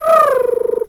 Animal_Impersonations
pigeon_2_emote_10.wav